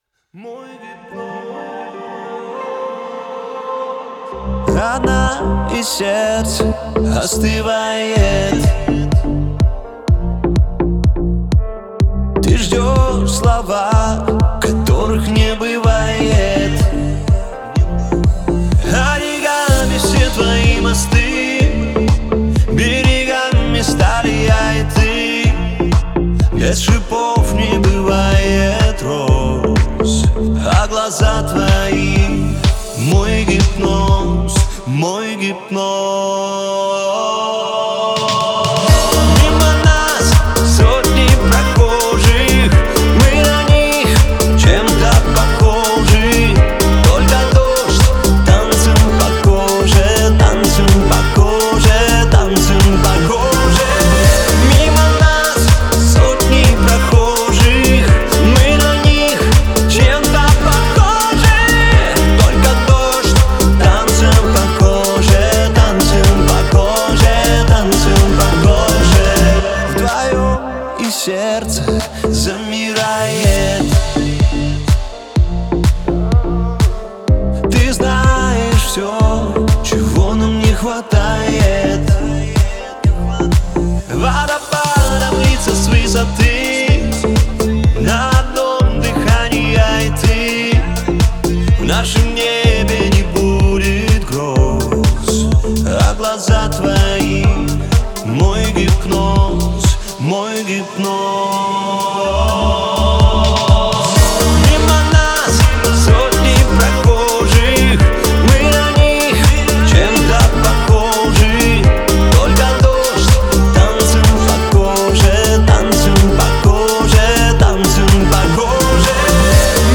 выполненный в жанре альтернативного рока с элементами инди.